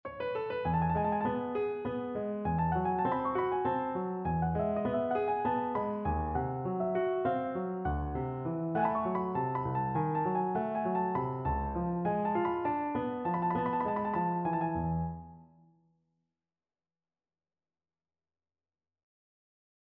This project included the task for me to write three different ornamented versions of the following melody written for the piano: